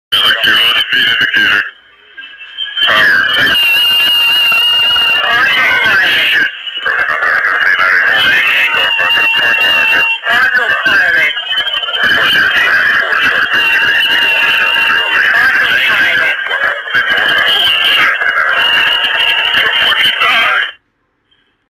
Comair Flight 3272 With CVR sound effects free download